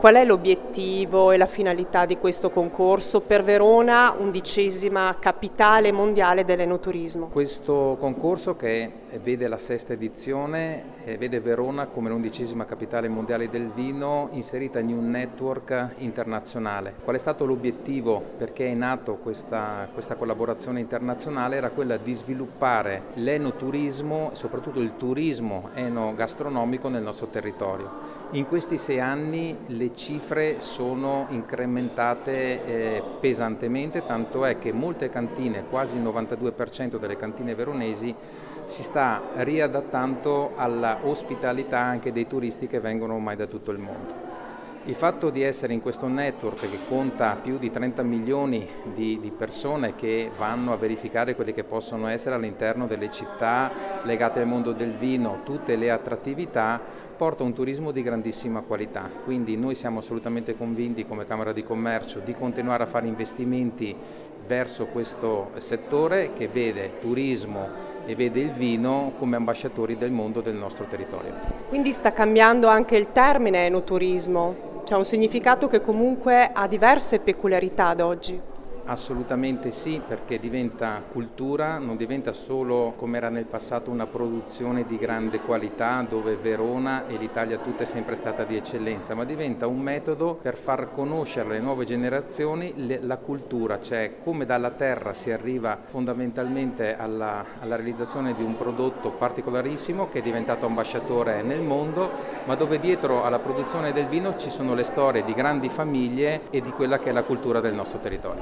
Al microfono della nostra corrispondente